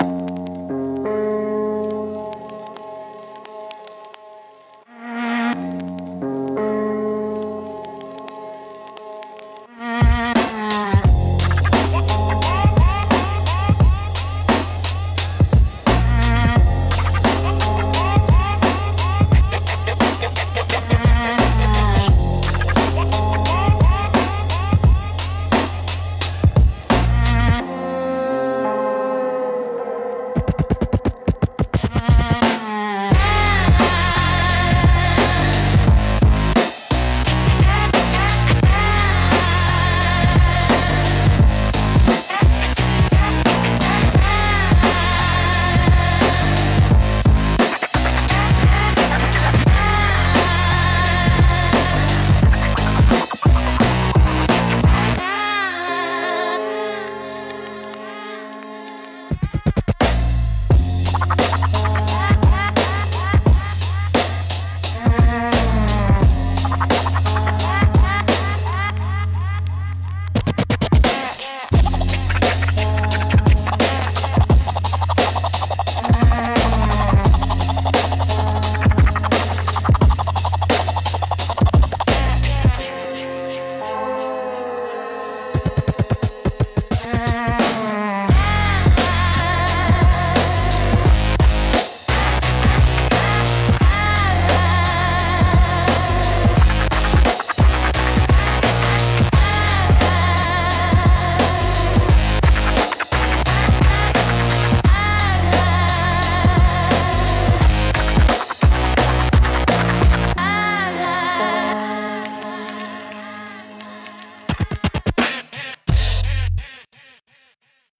Instrumental - Echo Chamber Hymns